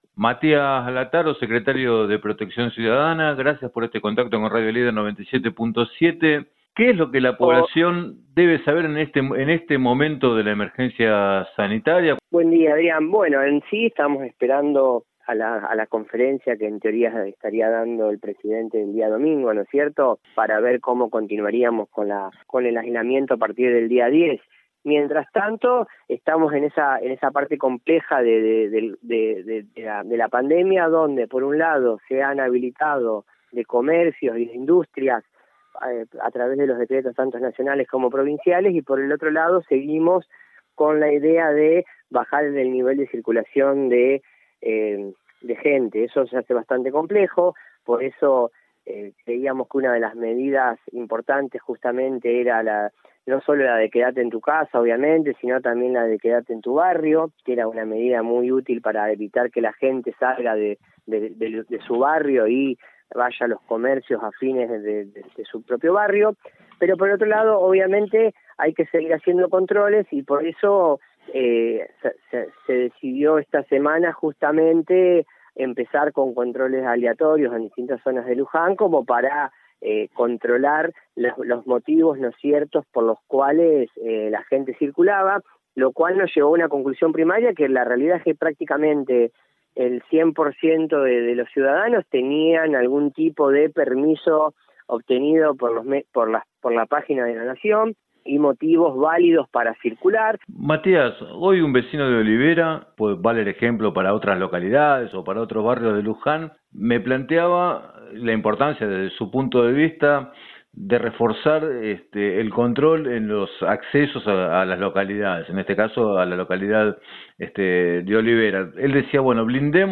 En declaraciones a Radio Líder 97.7 el funcionario consideró que en esta etapa es fundamental la conciencia ciudadana y sus correspondientes precauciones cotidianas para controlar la expansión de la pandemia.